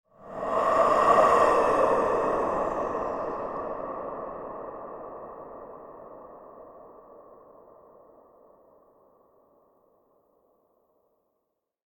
zapsplat_horror_breath_reverb_ghost_001_10801
Tags: ghost